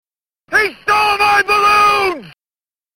The stage is on a timer, and even if you don't collect a single balloon, you'll go to the next stage when the time is up, as the Joker wails